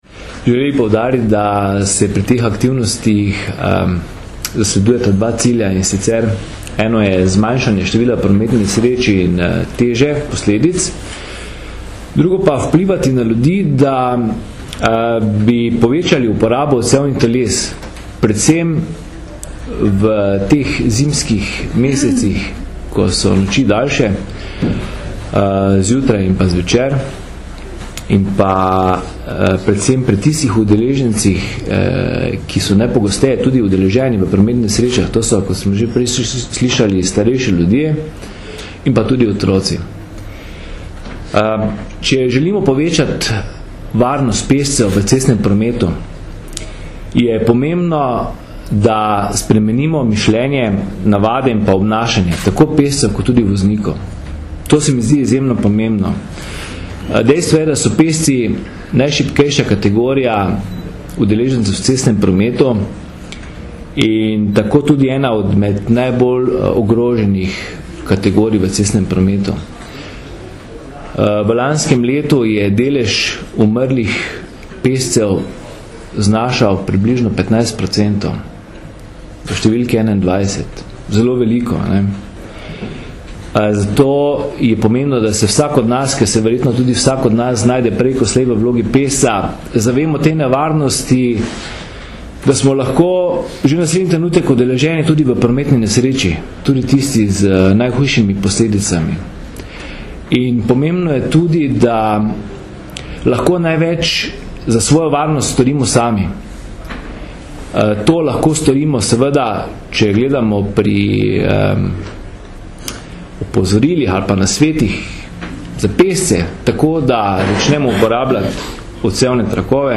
Številne aktivnosti AVP - SPV, SPV Občine Pivka, Slovenskega Karitasa in seveda policije so bile predstavljene danes, 3. februarja 2012, na novinarski konferenci.
Zvočni posnetek izjave